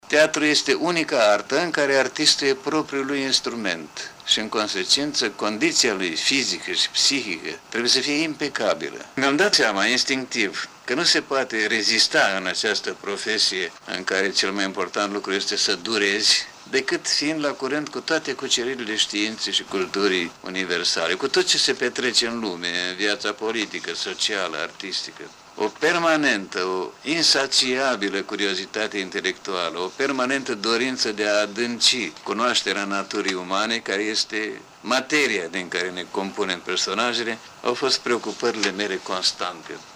Maestrul Radu Beligan – într-unul din interviurile aflate în Fonoteca de aur a Radiodifuziunii despre „secretul” actorului complet: